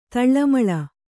♪ taḷḷamaḷa